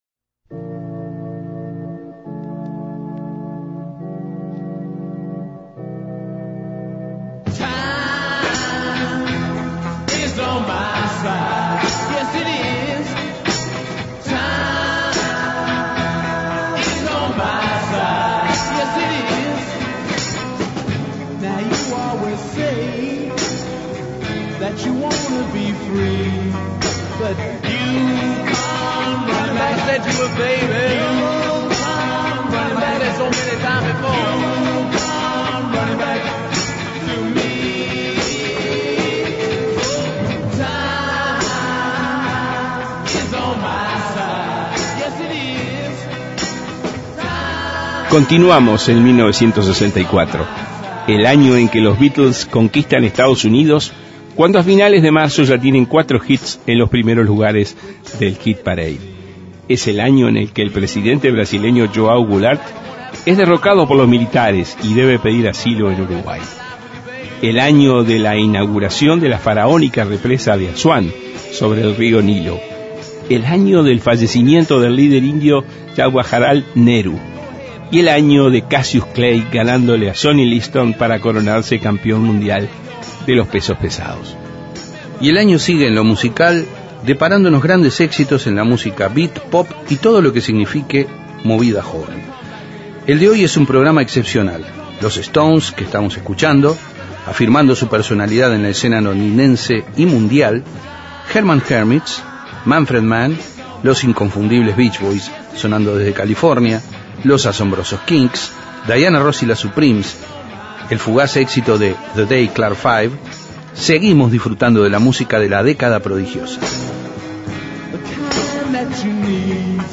Descargar Audio no soportado Y el año sigue en lo musical deparándonos grandes éxitos en la música beat, pop y todo lo que signifique movida joven. Un programa excepcional